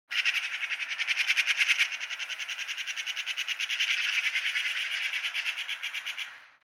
جلوه های صوتی
دانلود صدای جنگل 23 از ساعد نیوز با لینک مستقیم و کیفیت بالا
برچسب: دانلود آهنگ های افکت صوتی طبیعت و محیط دانلود آلبوم صدای جنگل از افکت صوتی طبیعت و محیط